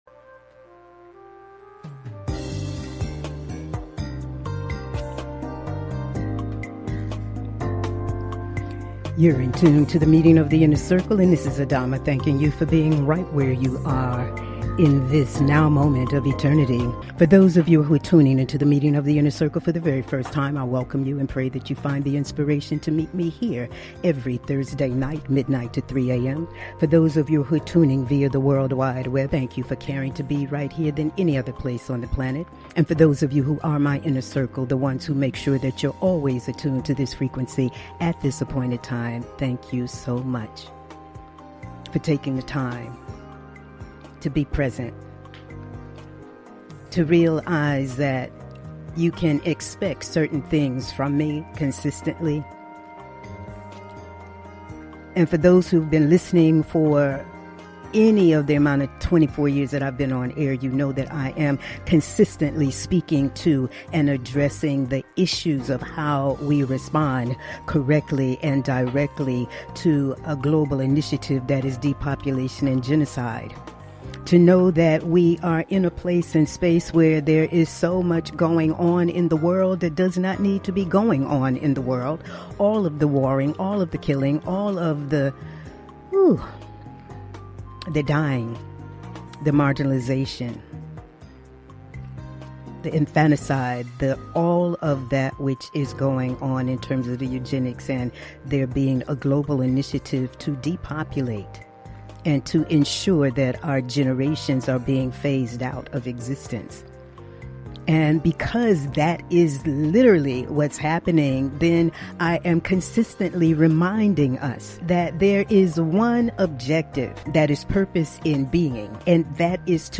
Talk Show Episode
Monologues